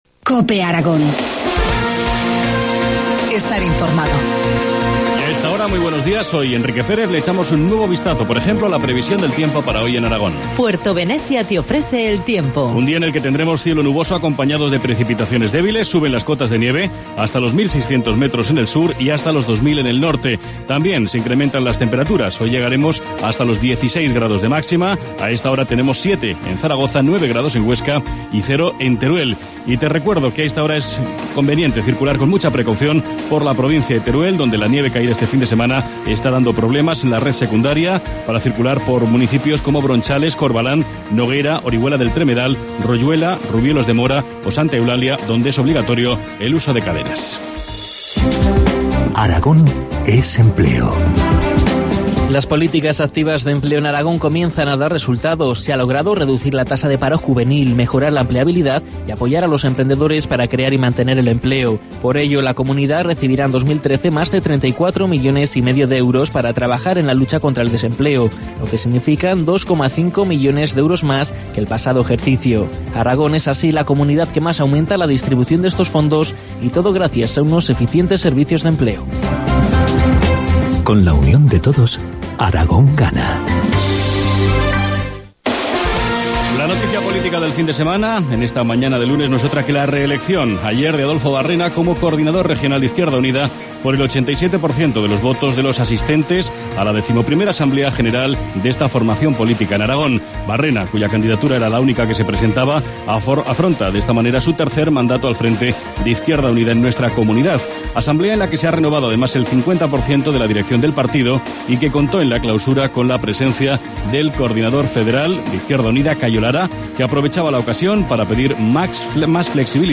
Informativo matinal, lunes 29 de abril, 8.25 horas